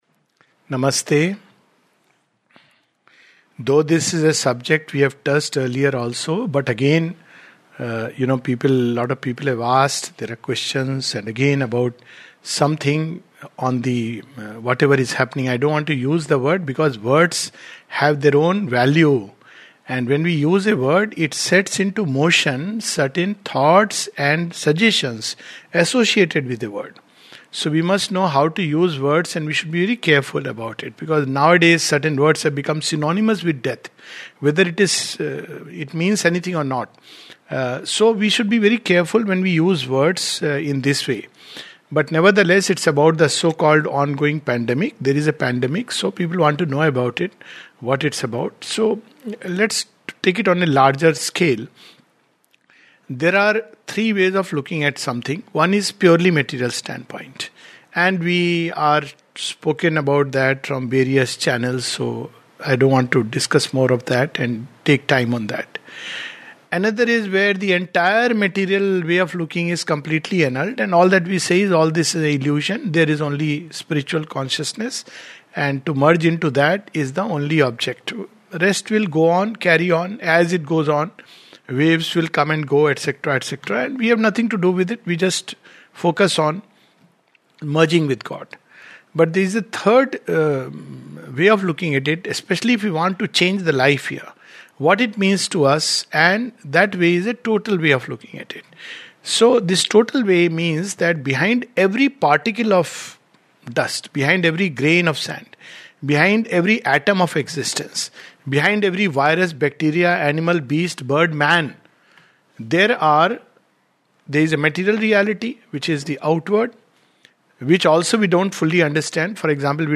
This talk is about a comprehensive view of the recent pandemic.